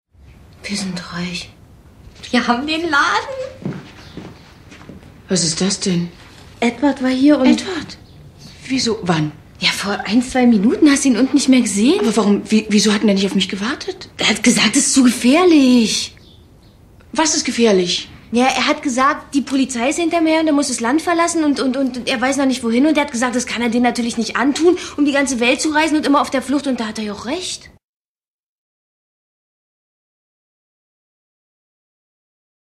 deutsche Sprecherin und Schauspielerin.
Sprechprobe: Industrie (Muttersprache):
german female voice over artist